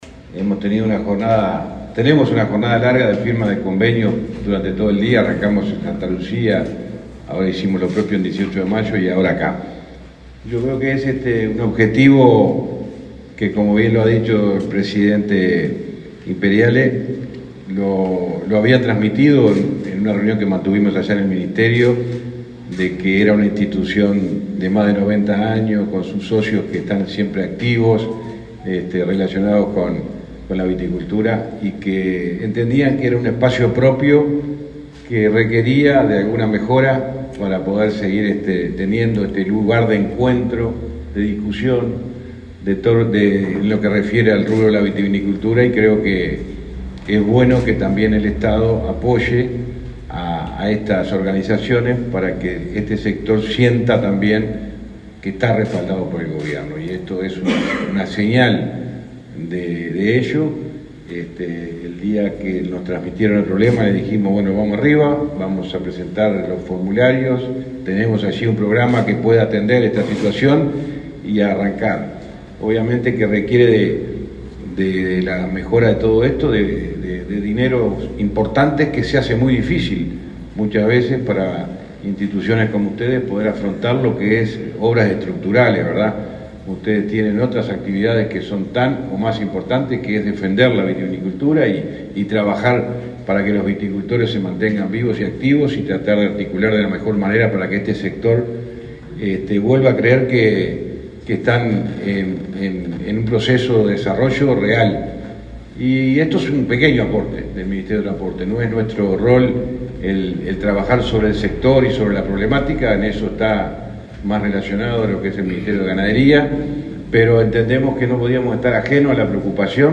Palabras del ministro de Transporte, José Luis Falero
En el marco de una recorrida por Canelones y Montevideo, el ministro de Transporte, José Luis Falero, firmó varios convenios sociales, visitó el